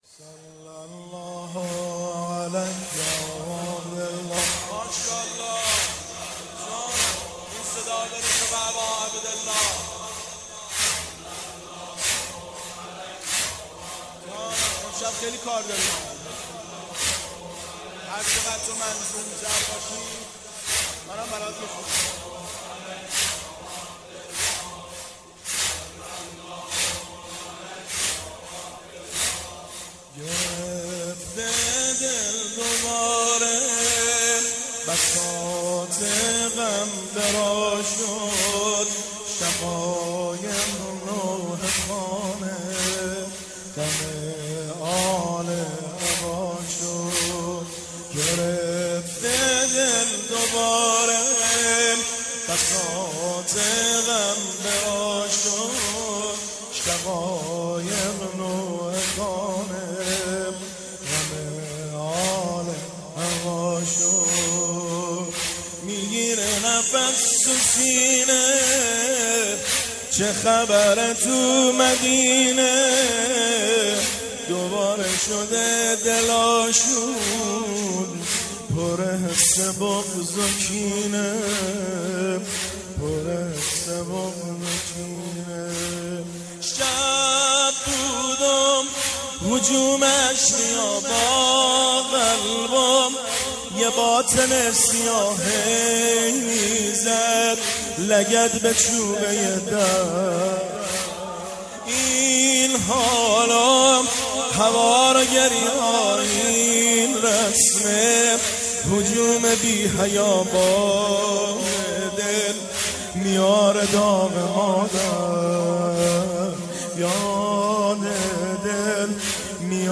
زمینه – شهادت امام جعفر صادق (علیه السلام) 1398